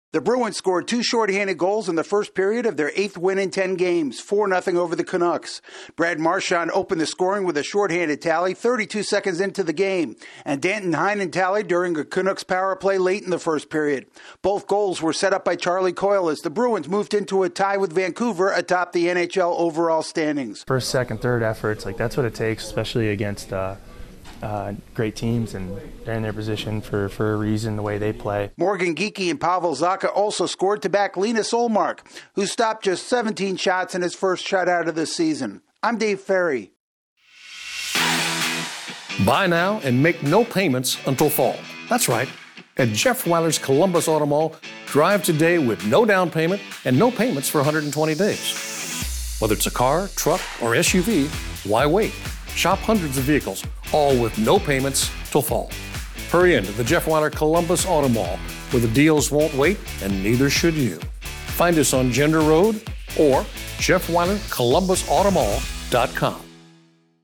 The Bruins get offense from their penalty killers in a shutout win against the top team in the Western Conference. AP correspondent